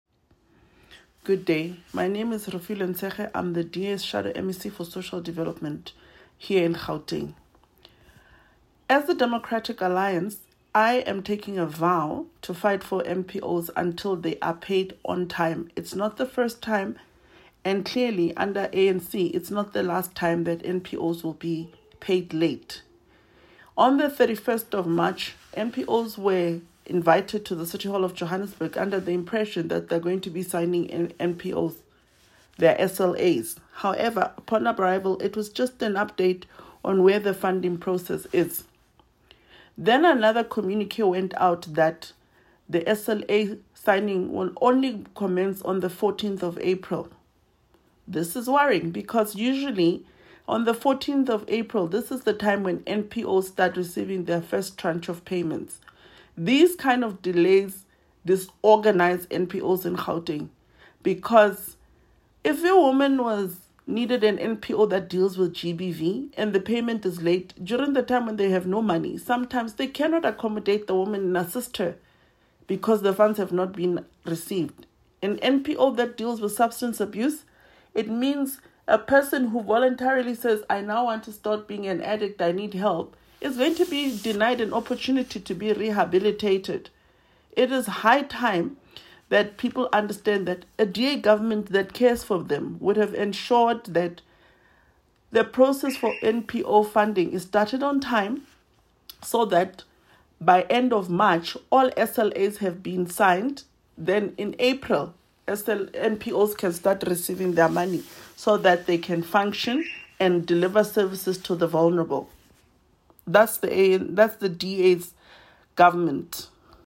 Sesotho soundbite by Refiloe Nt’sekhe MPL.